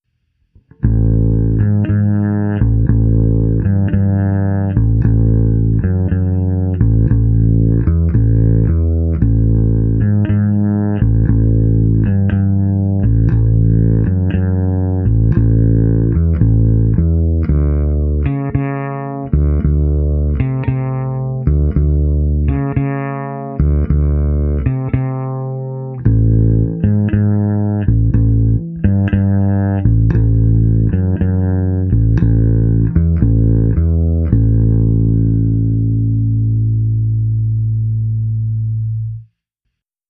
Hráno všechno s polohou pravé ruky u krku.
Poslední nahrávku, tedy na snímač u kobylky, jsem prohnal softwarem AmpliTube se simulací basového aparátu snímaného mikrofony.
Kobylkový snímač přes AmpliTube